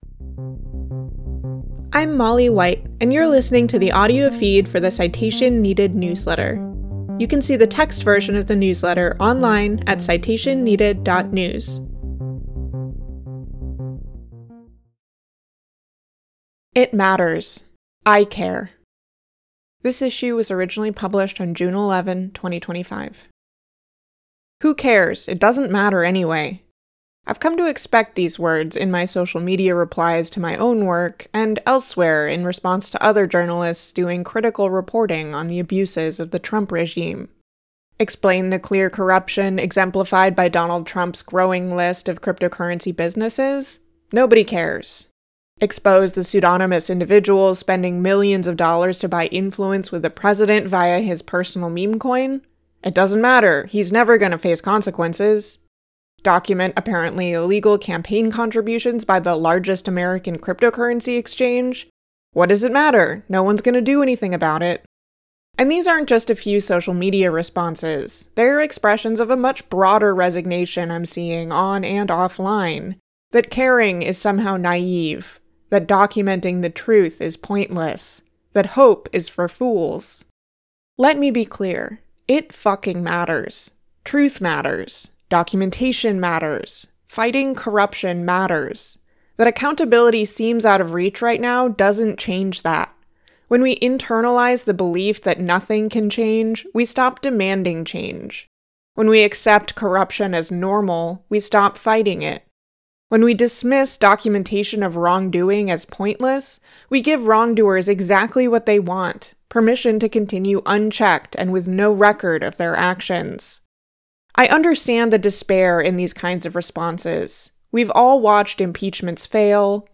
Listen to me read this post here (not an AI-generated voice!), subscribe to the feed in your podcast app, or download the recording for later.